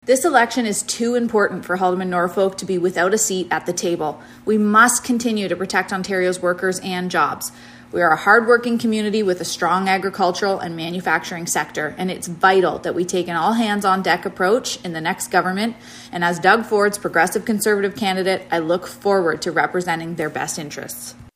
We are reaching out to candidates to come in for an interview about why they want to serve as Haldimand-Norfolk’s Member of Provincial Parliament.